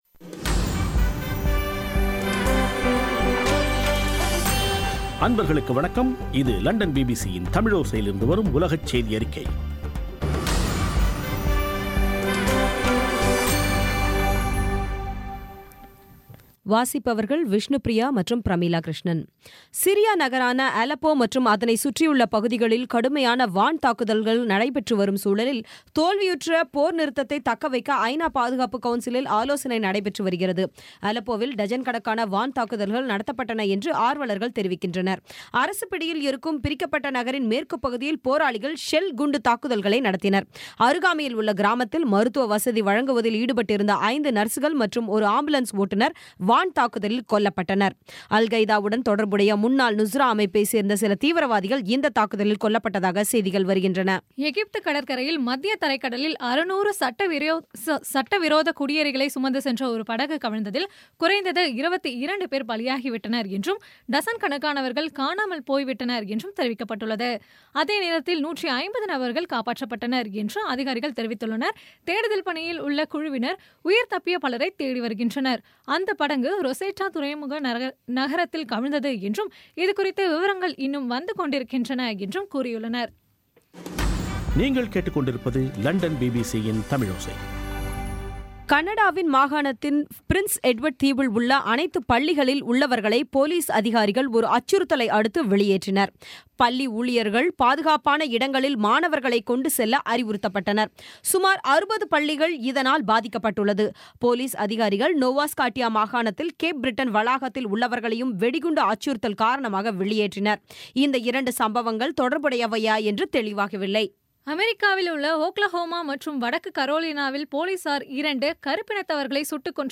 இன்றைய (ஆகஸ்ட் 21ம் தேதி ) பிபிசி தமிழோசை செய்தியறிக்கை